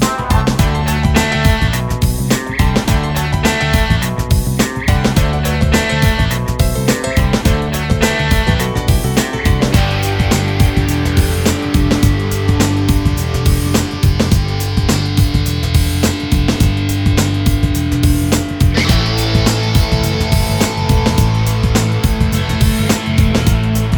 Minus Guitars Indie / Alternative 5:20 Buy £1.50